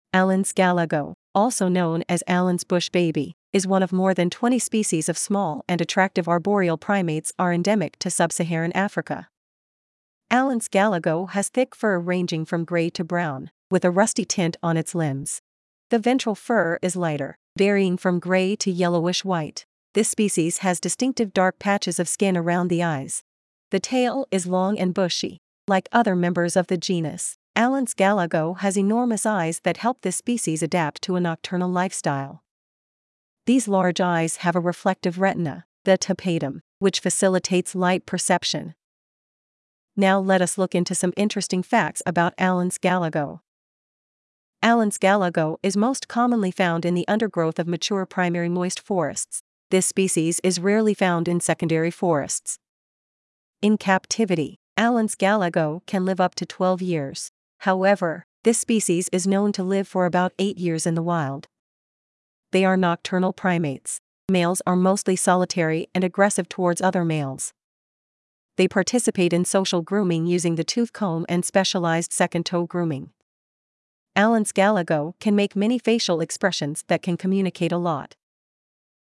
Allen's Galago
Allens-Galago.mp3